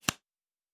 Cards Place 06.wav